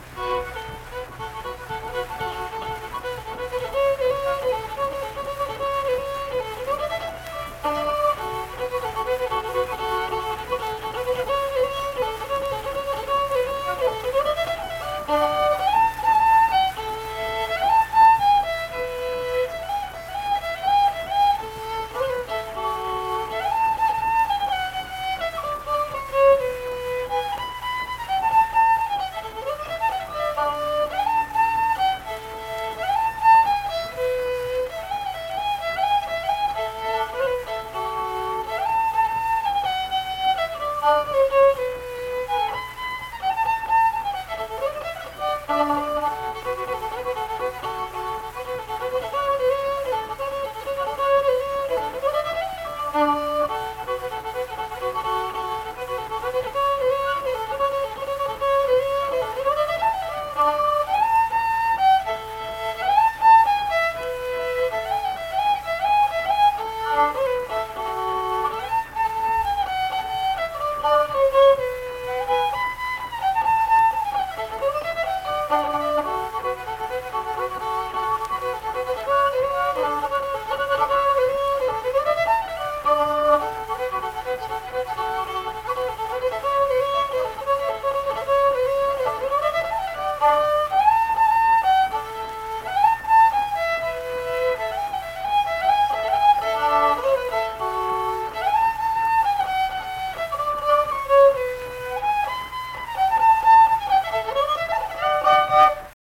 Unaccompanied fiddle music
Verse-refrain 3(2).
Instrumental Music
Fiddle
Harrison County (W. Va.)